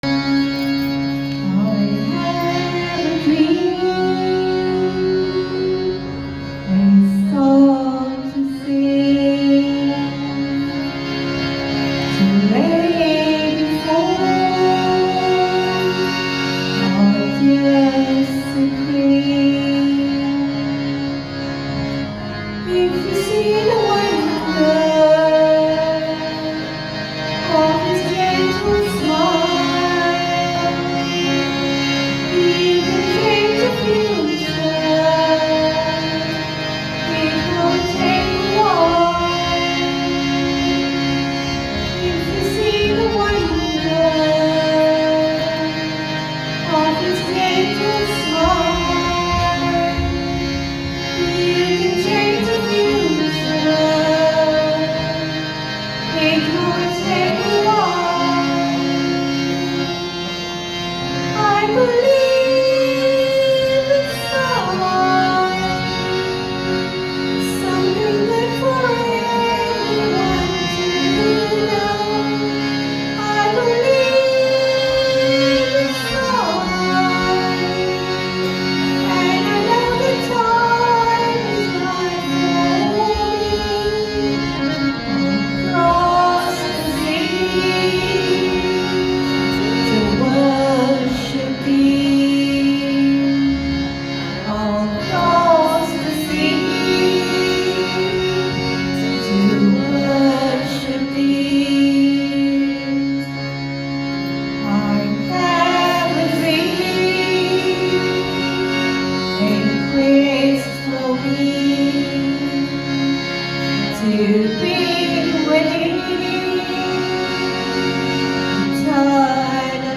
1. Devotional Songs
Major (Shankarabharanam / Bilawal)
8 Beat / Keherwa / Adi
3 Pancham / E
7 Pancham / B